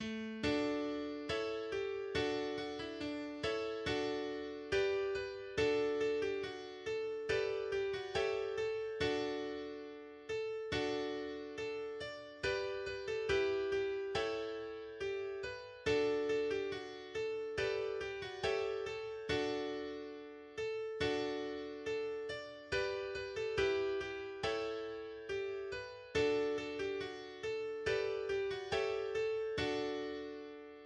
Melodie Volkslied